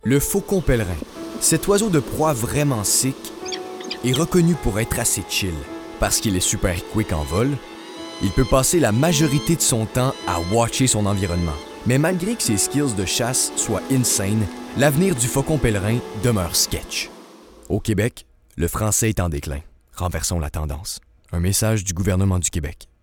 Démo voix - Pub gouvernementale